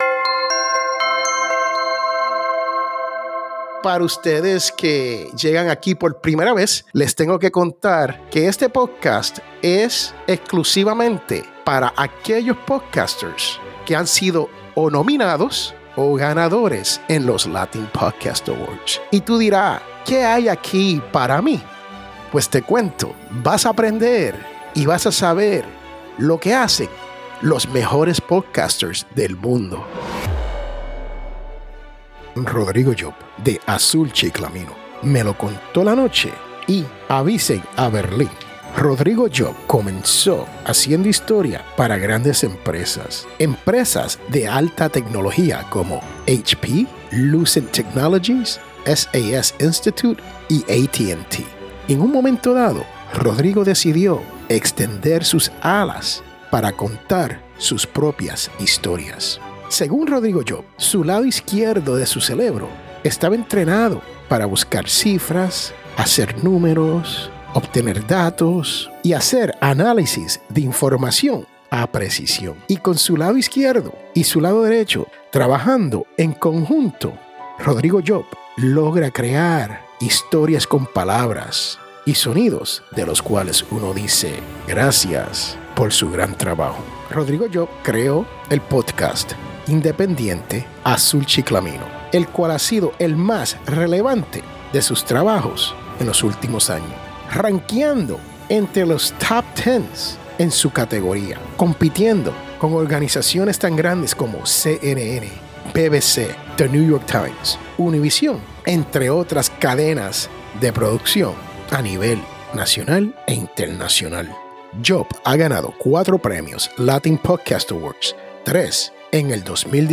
Masterclass